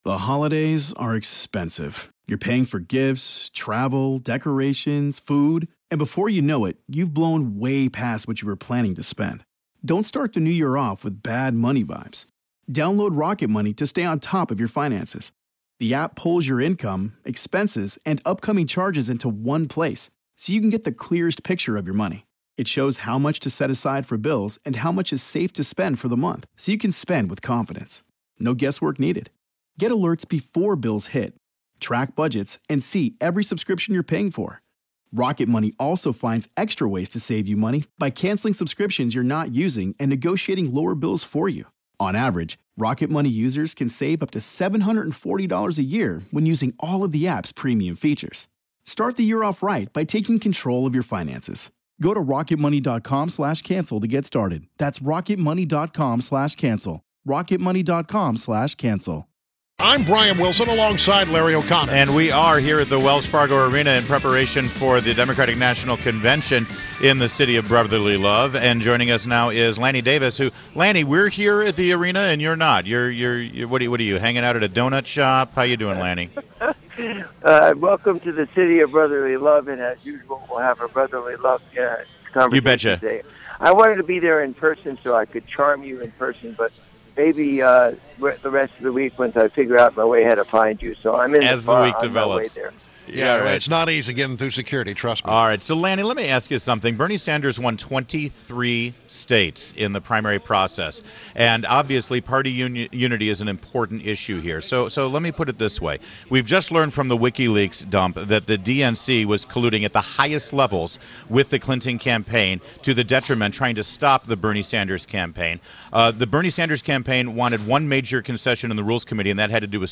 WMAL Interview - Lanny Davis - 07.25.16